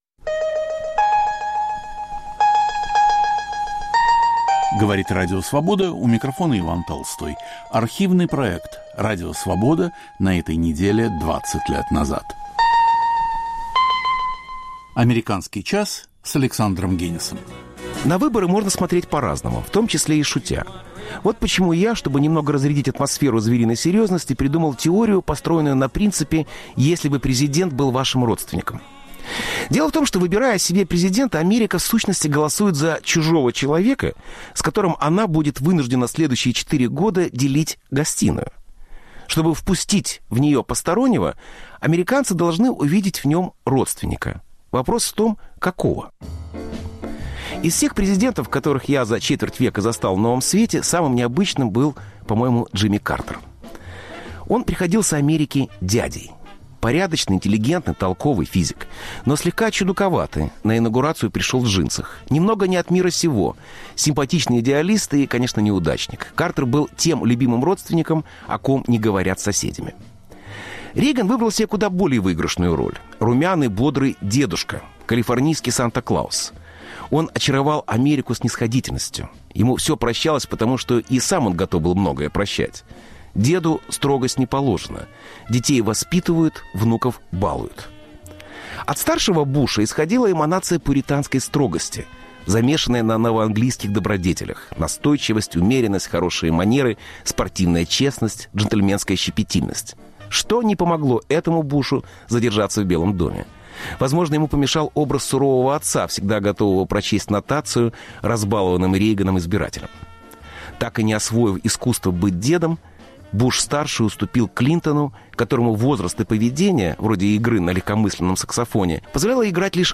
Автор и ведущий Александр Генис.